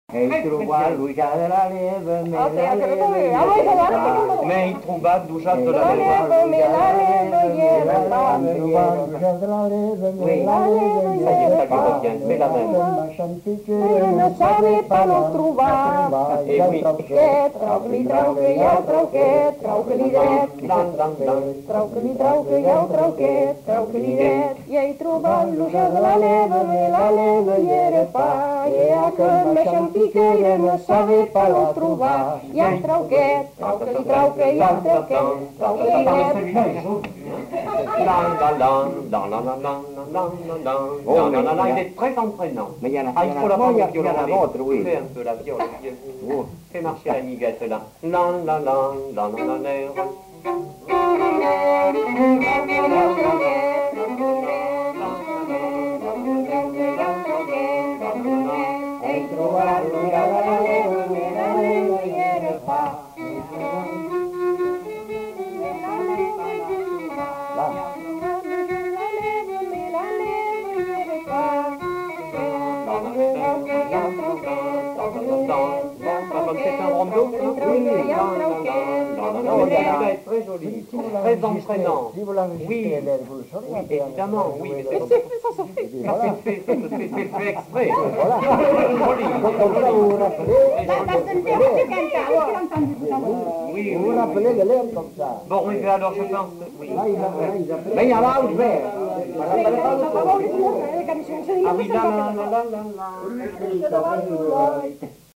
Aire culturelle : Grandes-Landes
Genre : chant
Type de voix : voix mixtes
Production du son : chanté
Danse : rondeau